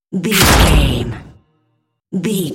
Dramatic hit bloody
Sound Effects
heavy
intense
dark
aggressive